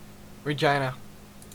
Ääntäminen
Ääntäminen CA Tuntematon aksentti: IPA : /ɹɪˈdʒaɪnə/ IPA : /ɹɨˈdʒijnə/ Lyhenteet ja supistumat R. Haettu sana löytyi näillä lähdekielillä: englanti Käännös Erisnimet 1. Regina Määritelmät Erisnimet (formal, usually, italicized) The reigning queen .